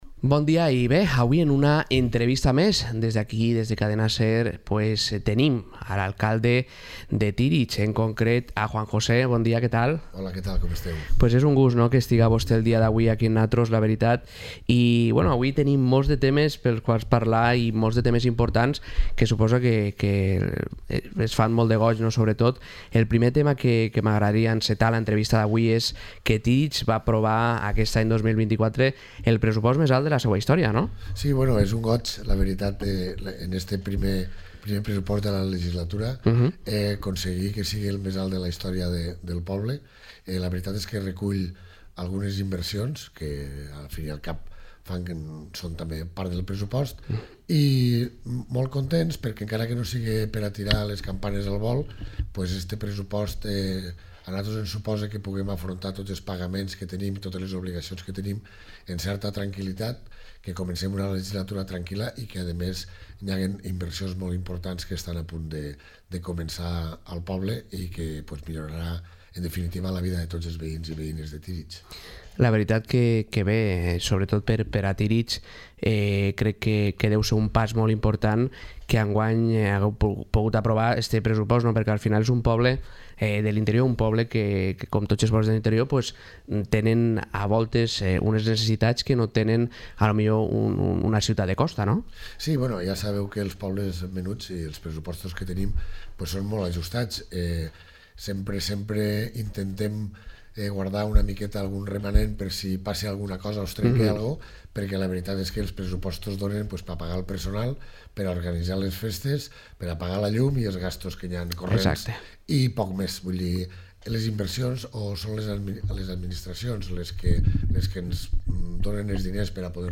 Podcast | Entrevista Juan José, alcalde de Tírig